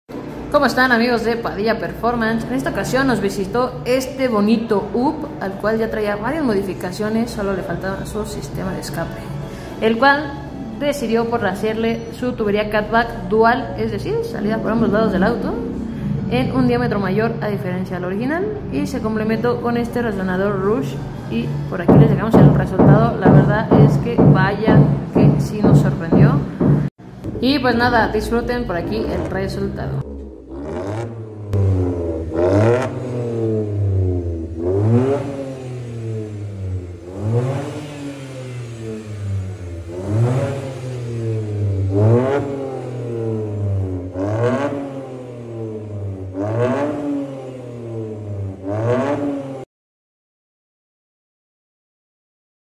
VW UP // Catback Dual Sound Effects Free Download